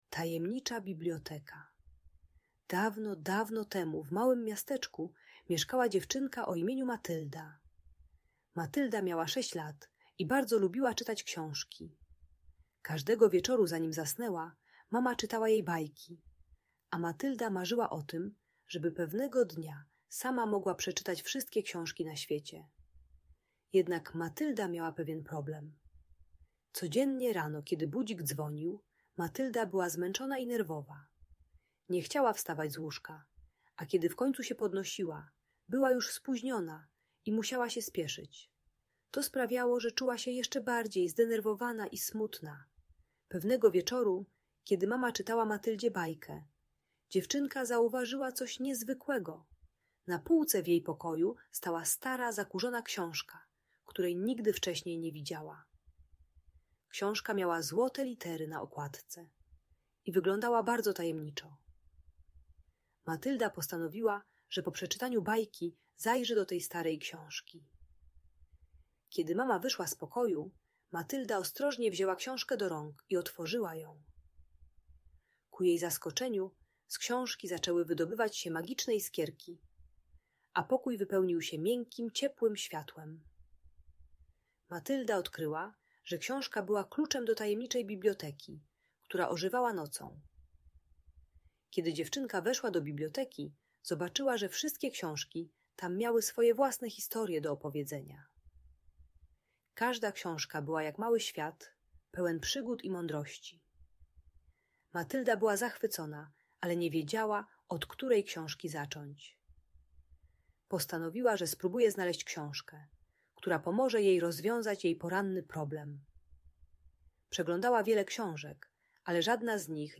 Zobacz inne audiobajki terapeutyczne